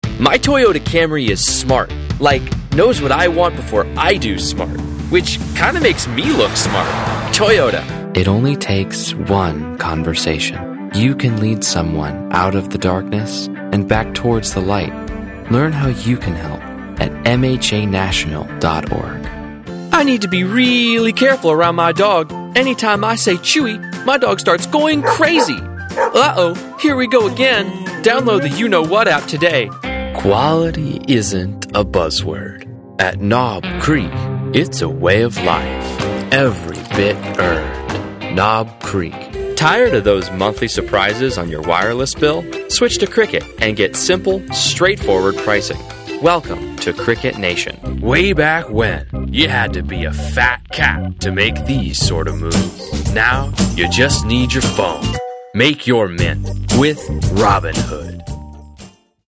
Authentic, Conversational, Executive, and Robust.
Commercial Demo
Male, 18-35.